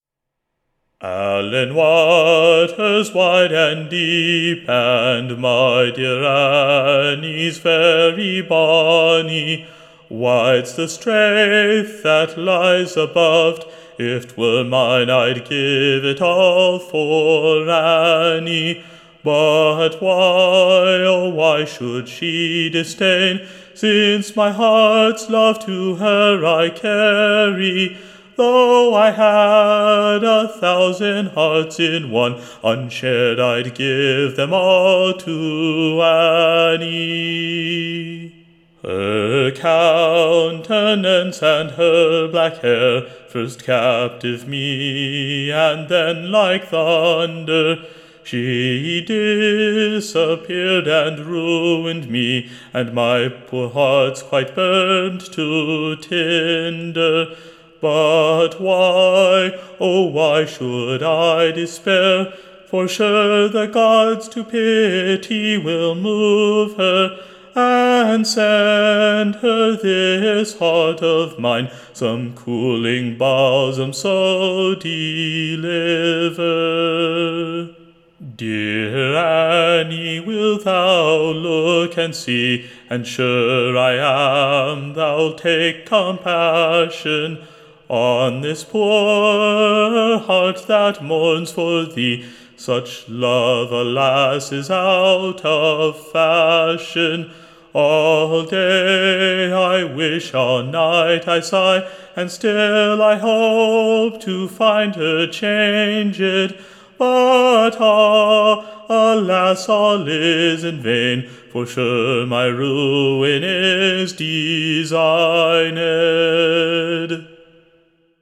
Recording Information Ballad Title [AL]LAN WATER / OR, A / [L]OVER / IN / [CA]PTIVITY / A / [N]EW SONG / Very much in request. Tune Imprint [Sung] with its own pleasant new Air. Standard Tune Title Banks of Allan Water Media Listen 00 : 00 | 4 : 56 Download Ry1.55.mp3 (Right click, Save As)